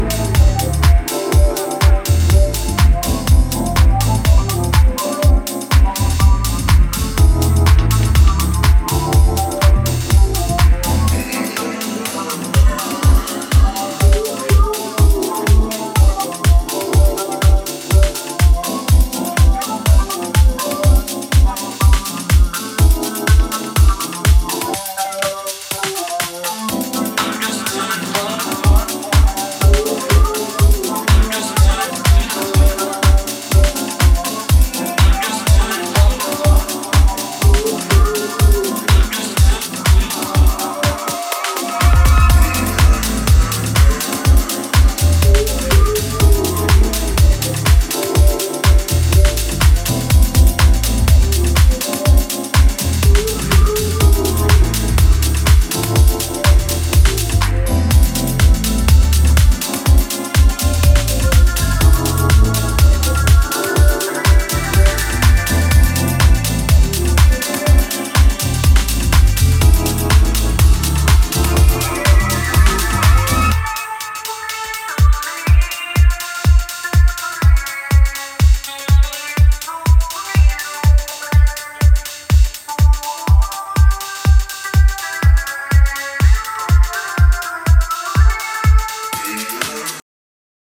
ジャズキーやコズミックなリード等でかなり個性的なテクスチャーを編む